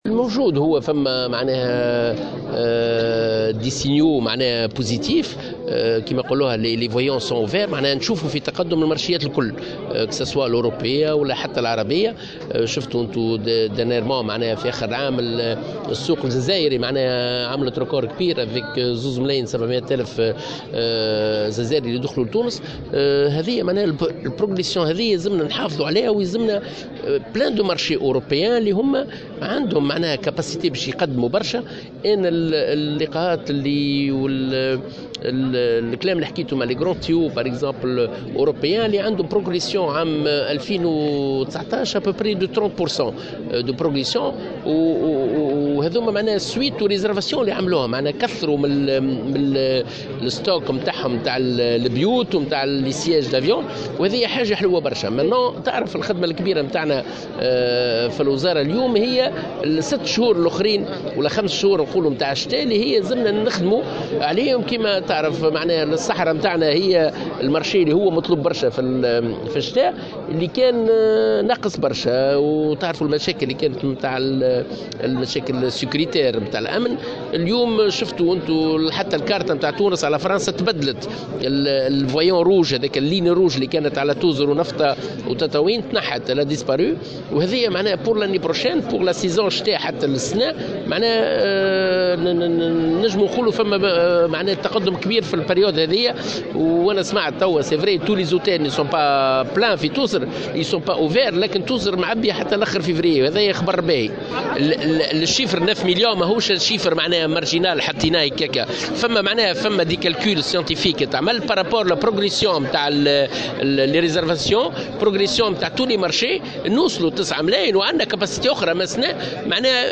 وأبرز الطرابلسي في تصريح لمراسلة الجوهرة أف أم، على هامش أشغال الملتقى السنوي لممثلي الديوان الوطني للسياحة، اليوم الأربعاء، أن بلوغ هذا الرقم يستدعي تواصل الاستقرار الأمني وتنويع المنتوج السياحي الوطني، مشددا على أهمية التركيز على السياحة الصحراوية واستغلال رفع الحظر عن المناطق الصحراوية من قبل بعض الدول الأوروبية.